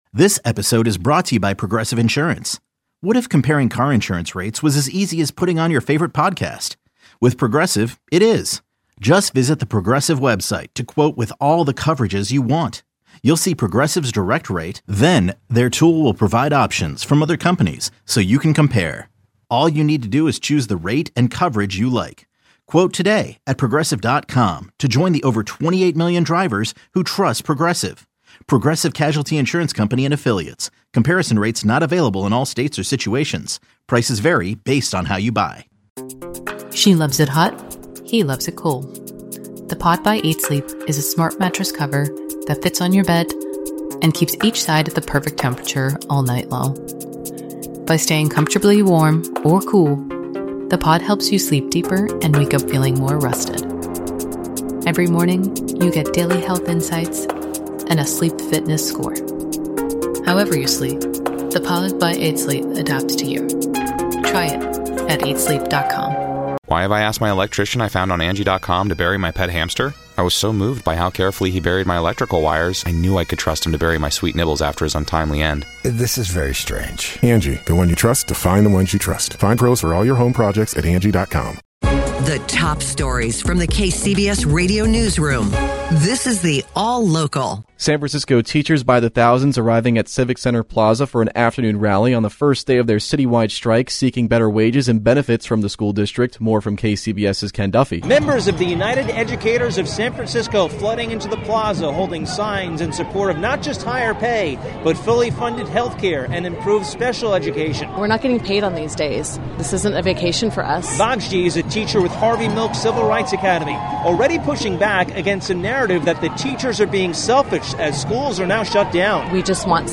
Be a guest on this podcast Language: en Genres: News Contact email: Get it Feed URL: Get it iTunes ID: Get it Get all podcast data Listen Now...